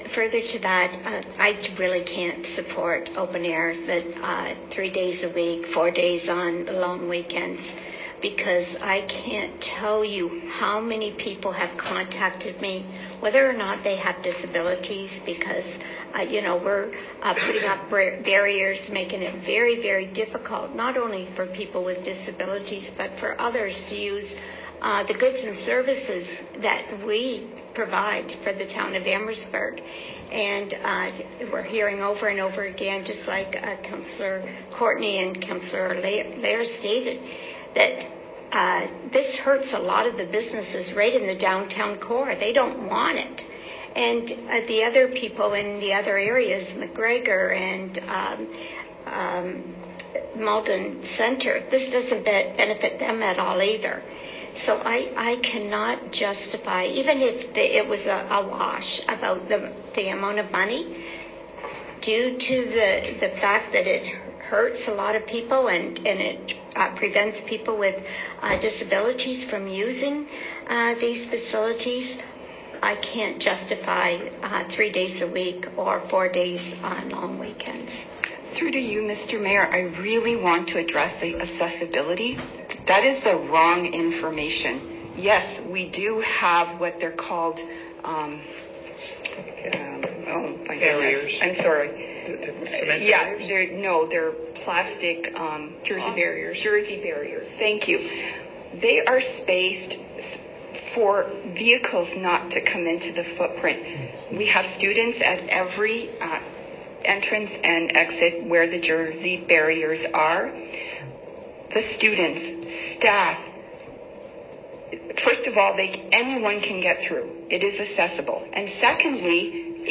the audio of a portion of the February 22, 2023 meeting and a portion of the discussion about accessibility barriers during the closed streets of Open Air; text is set out below.